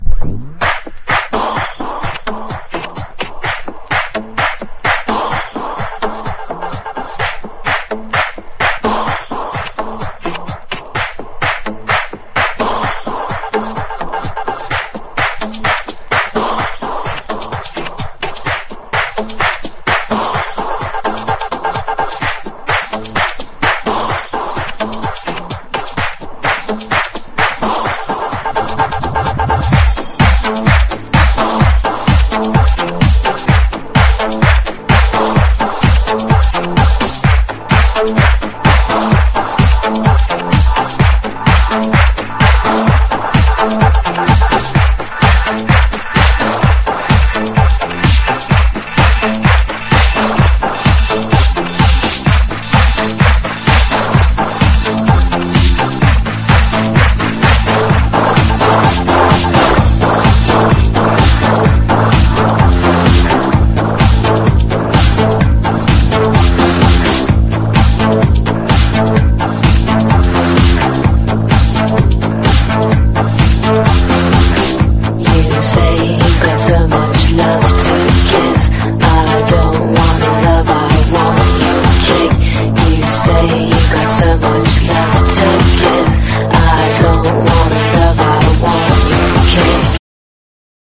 ELECTRO HOUSE / TECH HOUSE
エレクトロ＋ボーカル･ハウス！